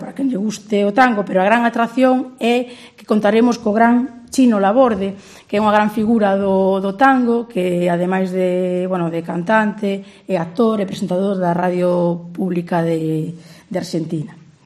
Pilar Lueiro es la concejala de fiestas de Santiago y presenta una novedad en la Ascensión: tango